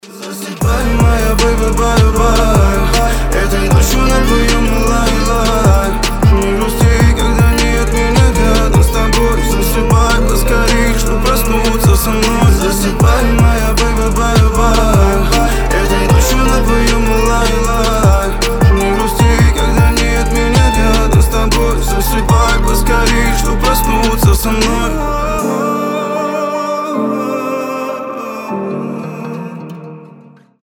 • Качество: 320, Stereo
мужской голос
лирика
колыбельные
Мелодичный попсовый рэп